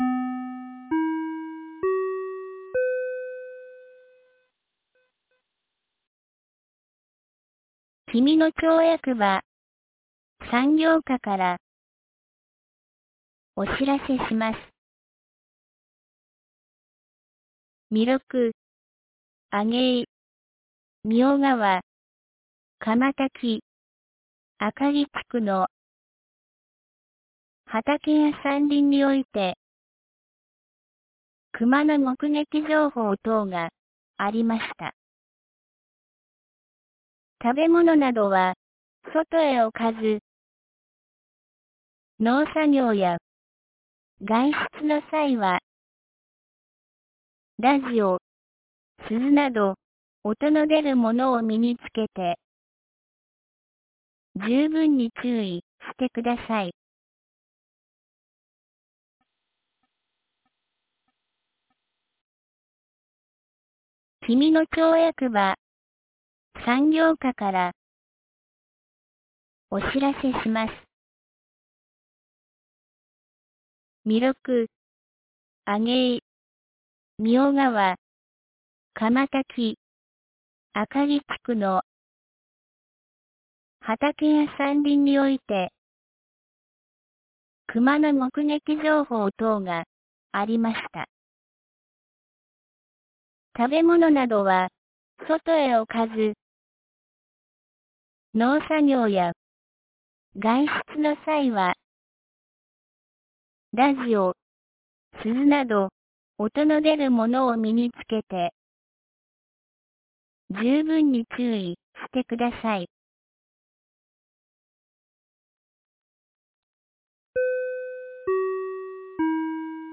2024年11月21日 17時07分に、紀美野町より上神野地区、下神野地区へ放送がありました。